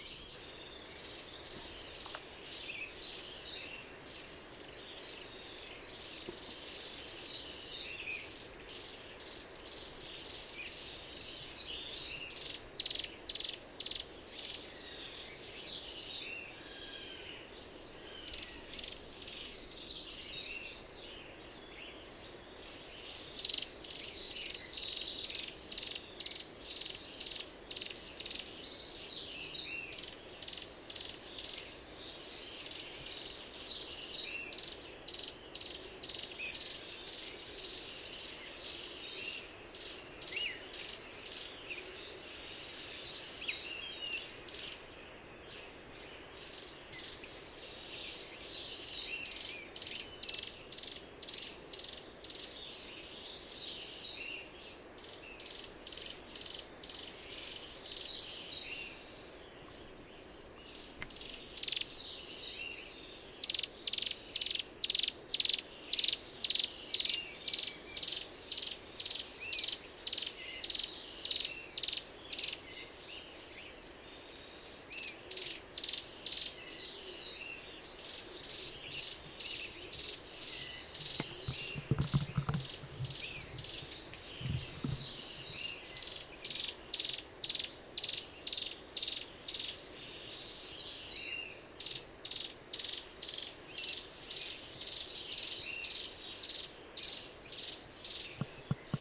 Rayadito (Aphrastura spinicauda)
Nombre en inglés: Thorn-tailed Rayadito
Fase de la vida: Adulto
Localidad o área protegida: Parque Nacional Nahuel Huapi
Condición: Silvestre
Certeza: Observada, Vocalización Grabada